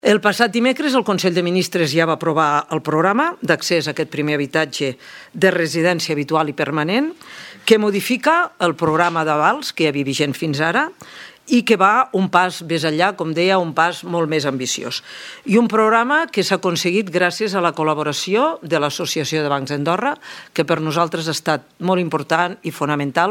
La ministra de Presidència, Economia, Treball i Habitatge, Conxita Marsol, ha explicat que el programa substitueix l’antic sistema d’avals, però va “un pas més enllà”.